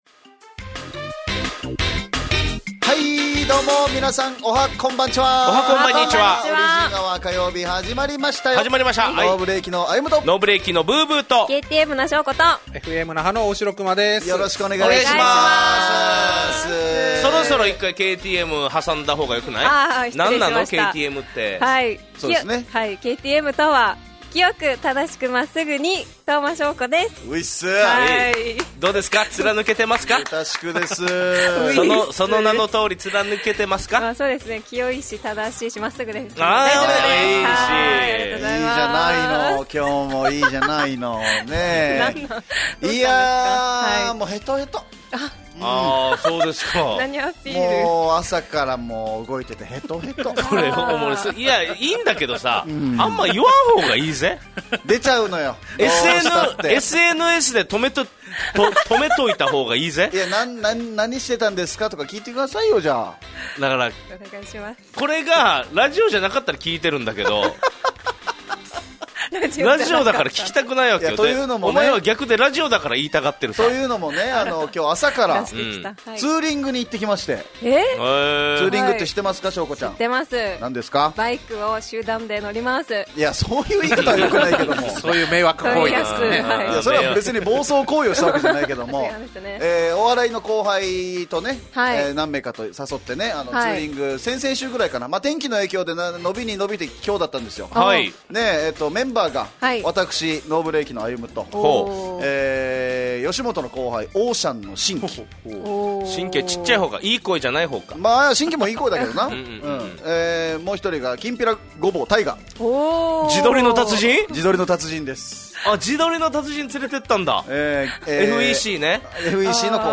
fm那覇がお届けする沖縄のお笑い集団・オリジンメンバー出演のバラエティ